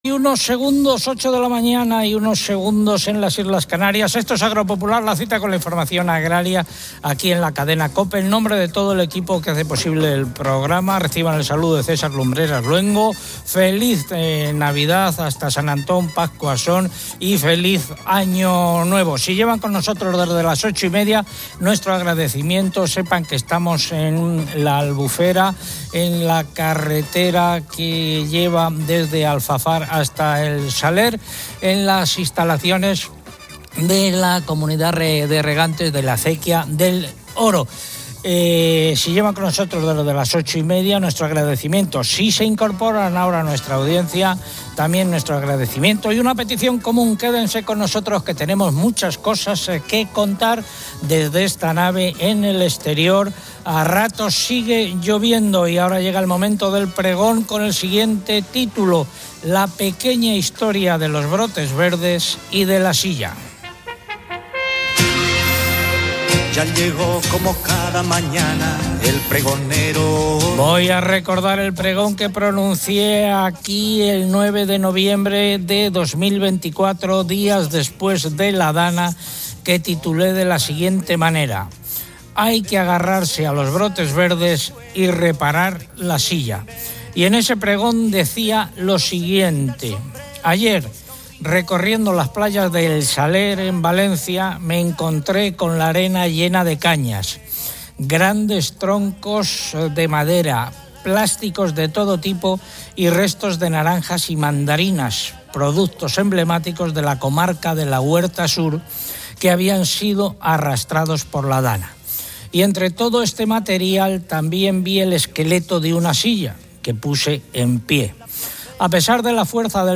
Agropopular emite desde la Albufera, conmemorando la superación de la DANA. Un invernadero devastado está ya reconstruido y prospera con nuevos cultivos de microbrotes y flores comestibles.